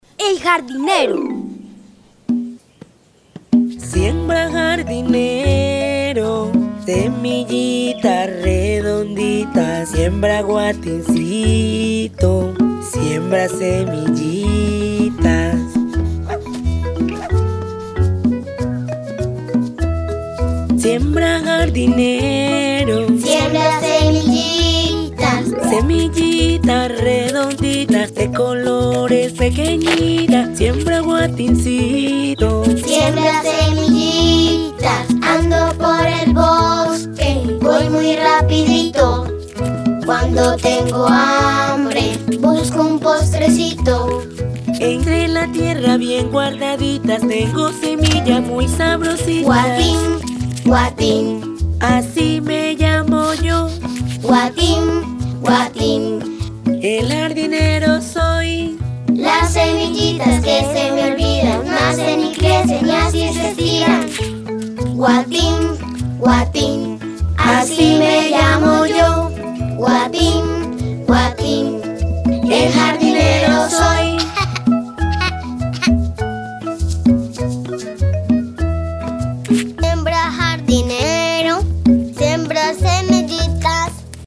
Escucha cantar a la abuela de Guillermina y Candelario